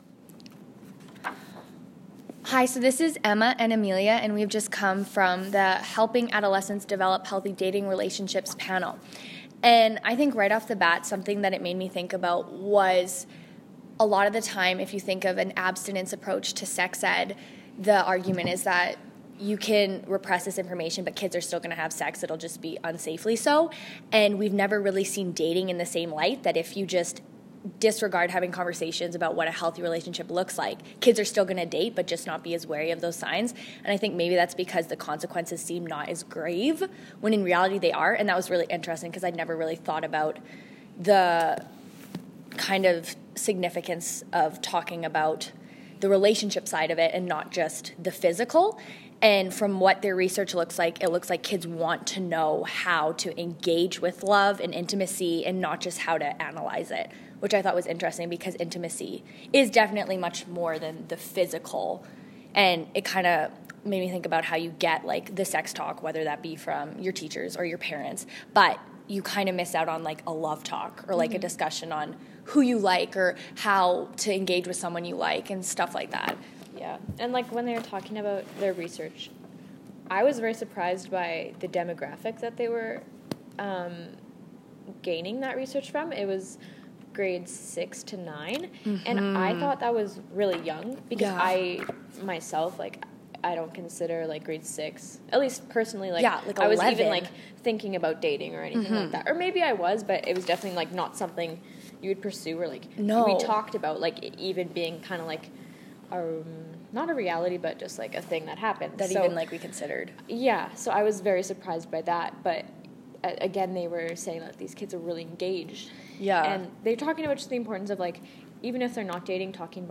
dating-violence-panel.m4a